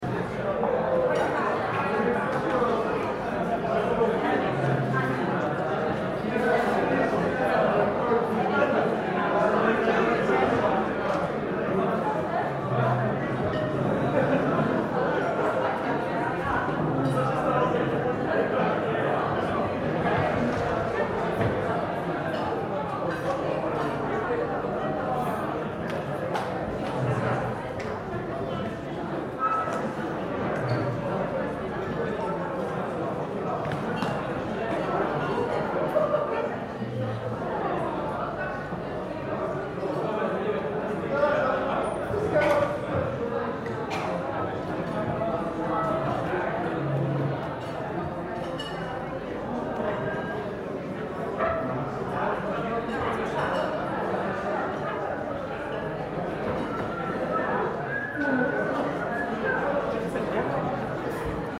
دانلود آهنگ مهمانی رستوران از افکت صوتی طبیعت و محیط
جلوه های صوتی
دانلود صدای مهمانی رستوران از ساعد نیوز با لینک مستقیم و کیفیت بالا